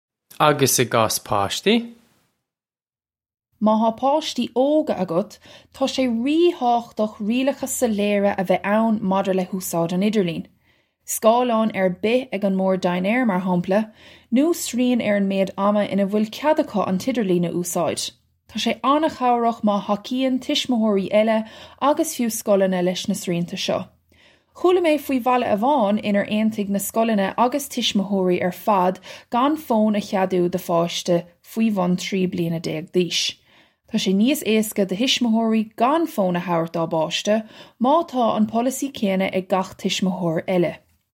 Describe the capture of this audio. Listen to the pronunciation here. This comes straight from our Bitesize Irish online course of Bitesize lessons.